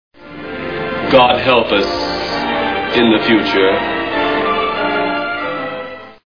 Plan 9 From Outer Space Movie Sound Bites